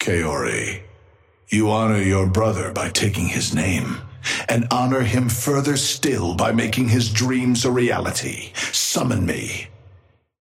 Amber Hand voice line - Kaori, you honor your brother by taking his name, and honor him further still by making his dreams a reality.
Patron_male_ally_yamato_start_05.mp3